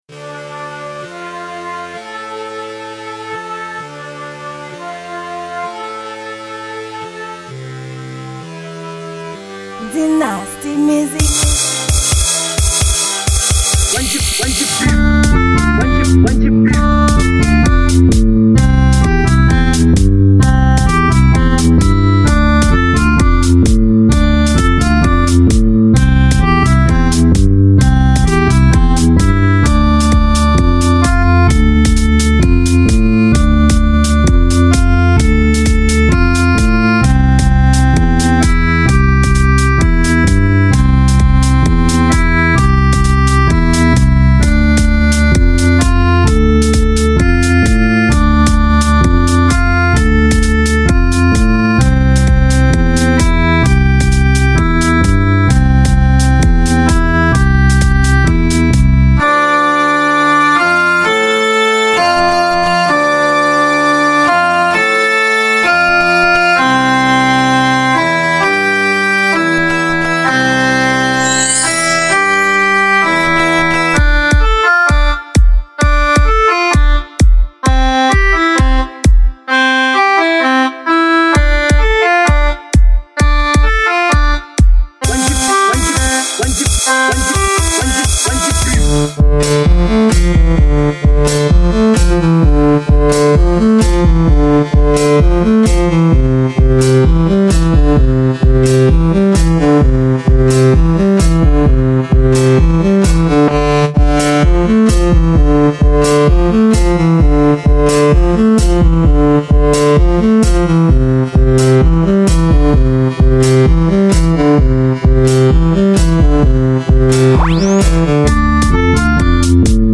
Genre: Beat